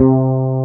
BAS.FRETC3-R.wav